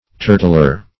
Search Result for " turtler" : Wordnet 3.0 NOUN (1) 1. someone whose occupation is hunting turtles ; The Collaborative International Dictionary of English v.0.48: Turtler \Tur"tler\ (t[^u]r"tl[~e]r), n. One who catches turtles or tortoises.